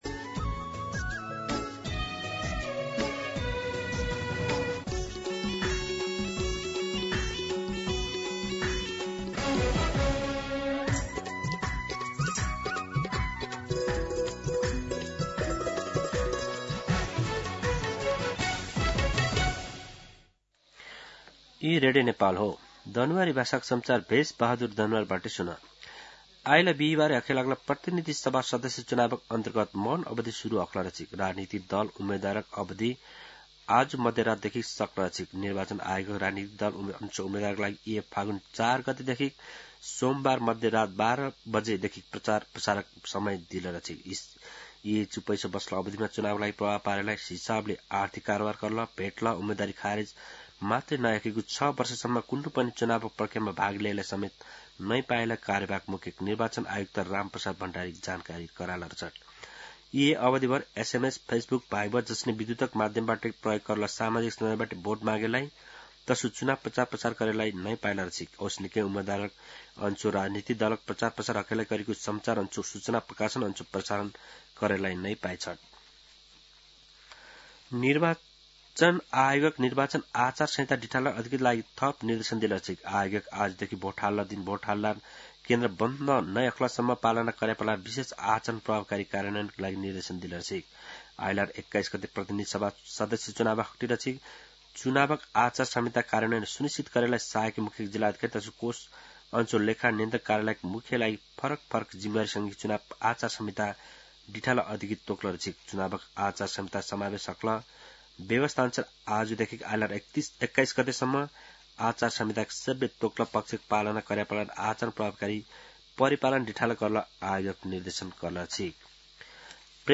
दनुवार भाषामा समाचार : १९ फागुन , २०८२
Danuwar-News-19.mp3